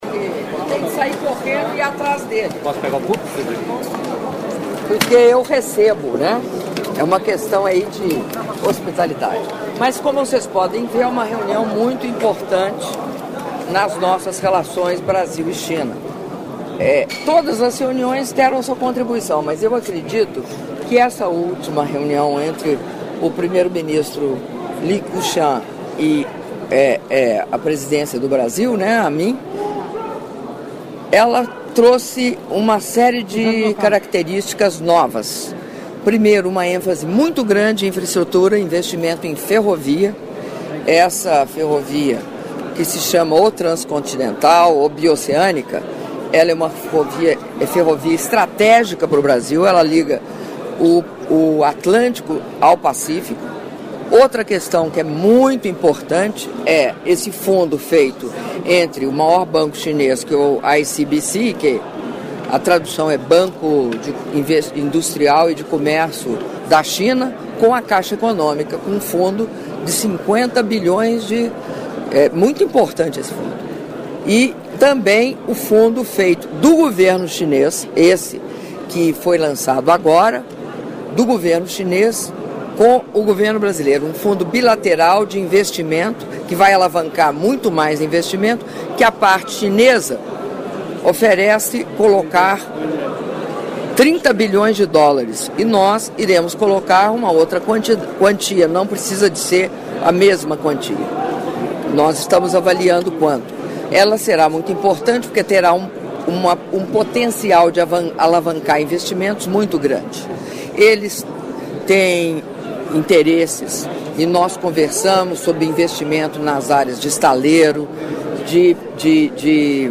Áudio da entrevista coletiva concedida pela presidenta da República, Dilma Rousseff, após cerimônia de assinatura de atos entre Brasil e China - Brasília/DF (4min11s)